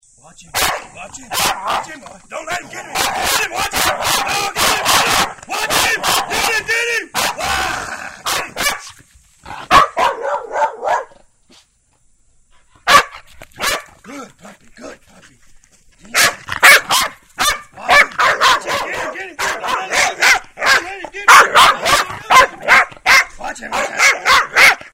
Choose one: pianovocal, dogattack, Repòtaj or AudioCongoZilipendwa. dogattack